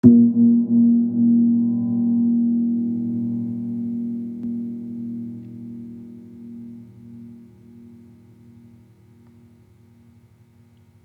Gong-A#2-f.wav